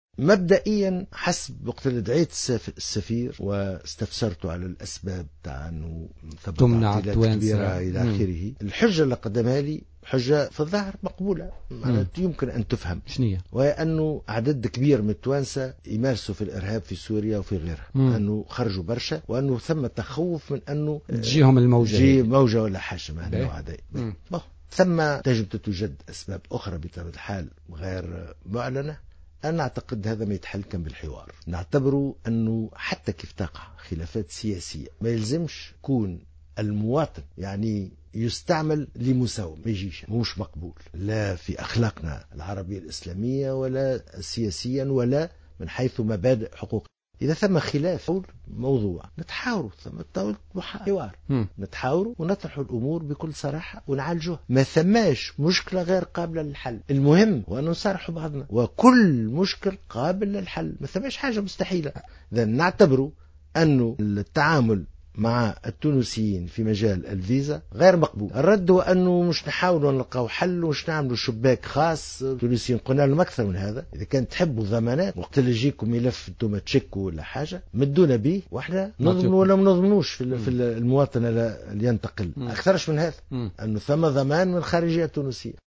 وأوضح في حوار مسجّل مع "الجوهرة أف أم" سيتم بثه ظهر الغد الاثنين، أن سفير الإمارات في تونس قدم له رسميا تفسيرا للتعطيلات التي يواجهها التونسيون عند طلب التأشيرة الإماراتية، وقال مرد هذا المشكل هو الإرهاب الذي أصبح ملتصقا بالتونسيين.